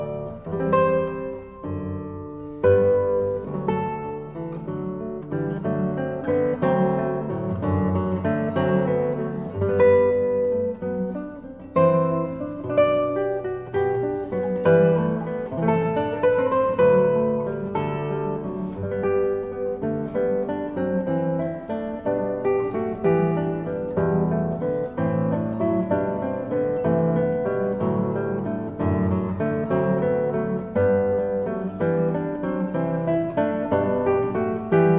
Christian